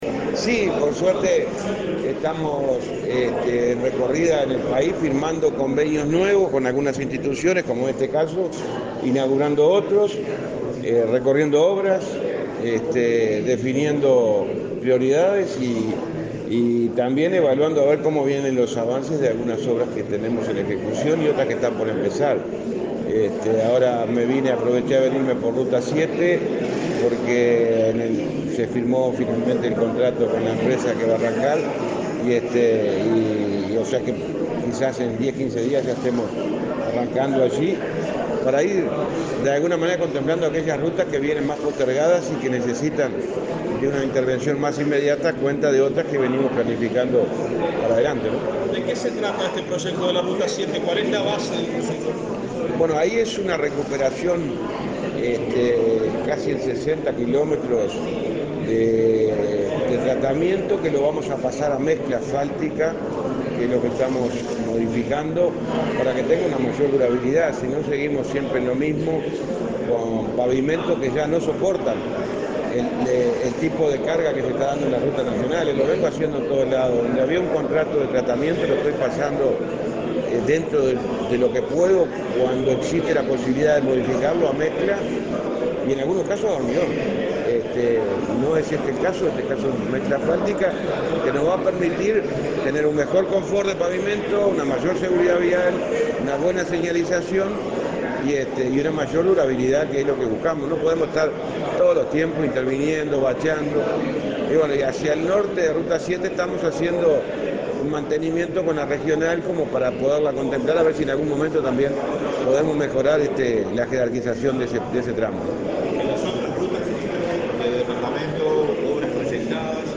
Declaraciones del ministro de Transporte, José Luis Falero
Declaraciones del ministro de Transporte, José Luis Falero 19/09/2022 Compartir Facebook X Copiar enlace WhatsApp LinkedIn El ministro de Transporte, José Luis Falero, dialogó con la prensa acerca de su jornada de trabajo en Cerro Largo, en la que inauguró obras y firmó convenios sociales.